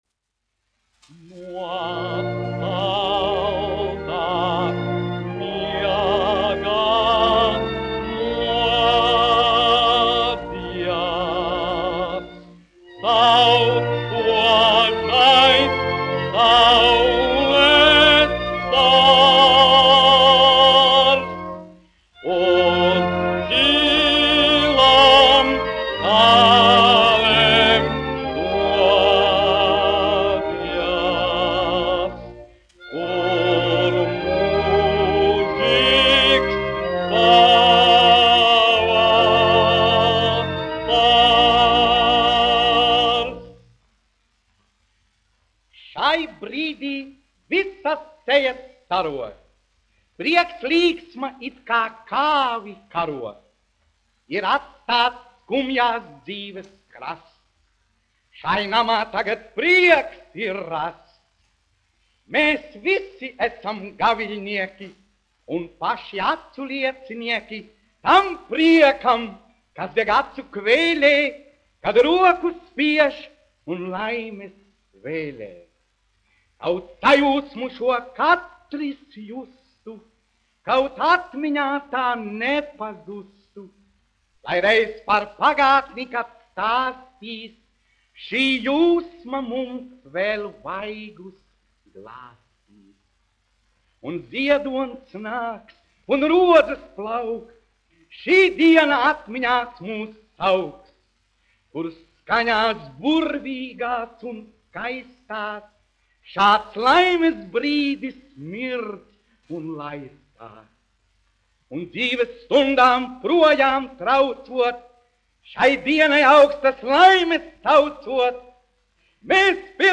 1 skpl. : analogs, 78 apgr/min, mono ; 25 cm
Monologi ar mūziku